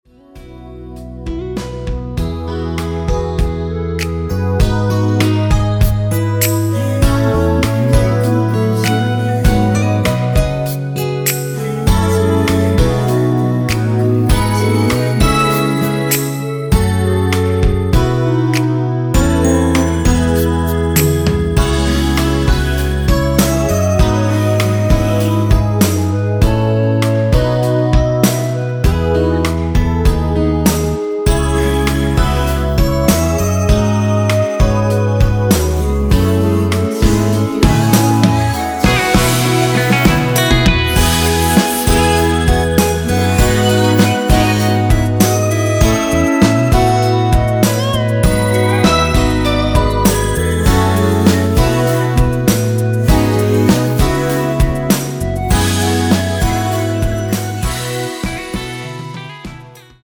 원키에서(+2)올린 멜로디와 코러스 포함된 MR입니다.(미리듣기 확인)
앞부분30초, 뒷부분30초씩 편집해서 올려 드리고 있습니다.
중간에 음이 끈어지고 다시 나오는 이유는